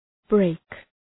Προφορά
{breık}